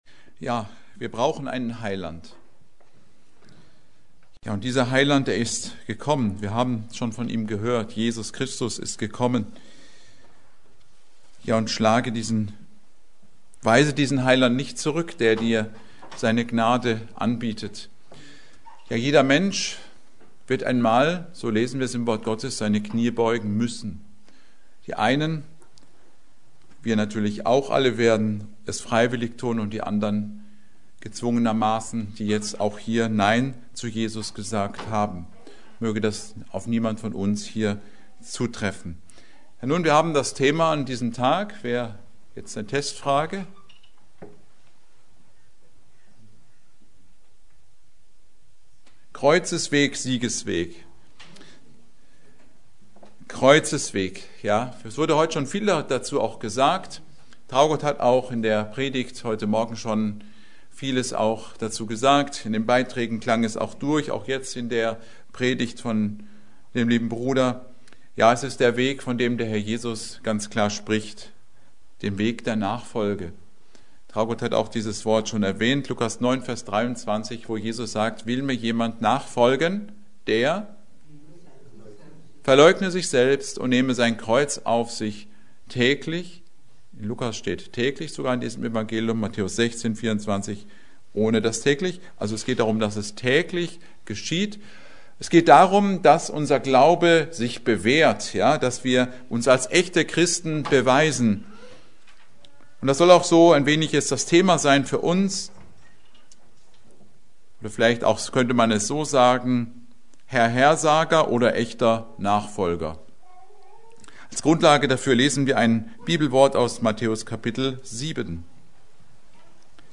Predigt: Karfreitag 2026